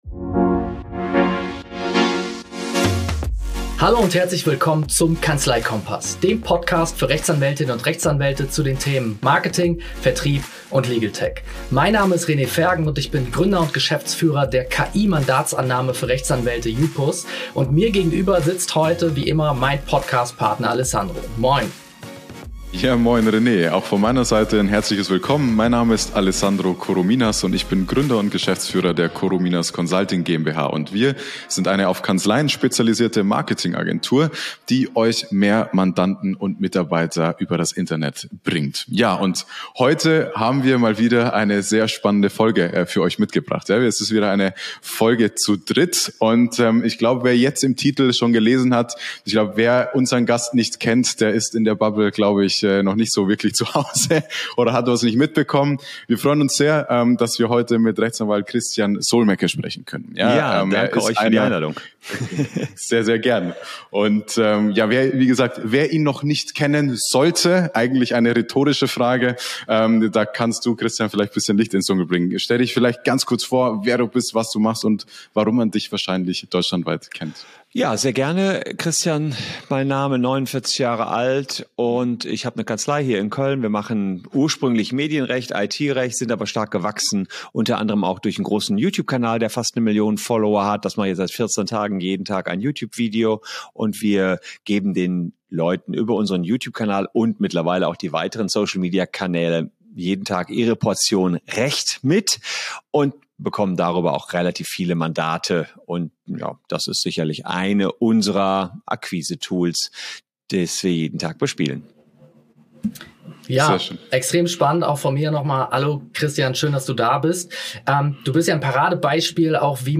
Beschreibung vor 2 Jahren In unserer neuen Folge des Kanzleikompass-Podcasts begrüßen wir einen ganz besonderen Gast: Christian Solmecke.